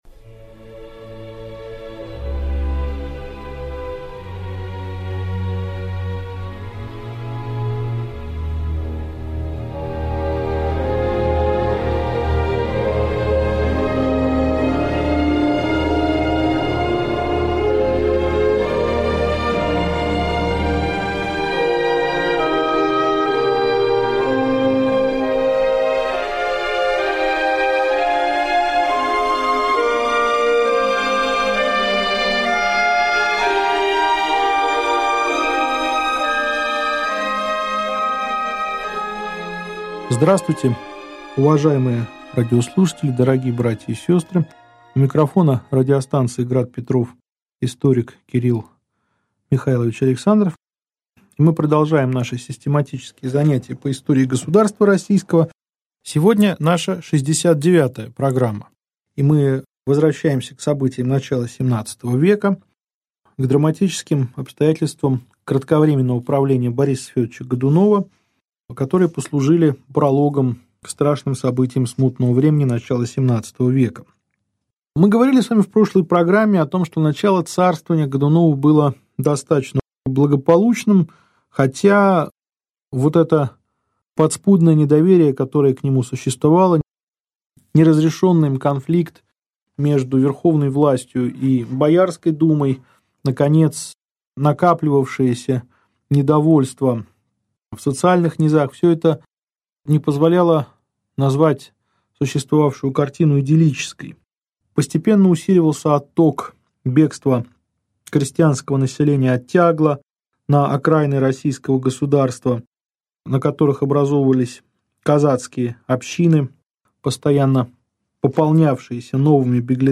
Аудиокнига Лекция 69. Правление Бориса Годунова | Библиотека аудиокниг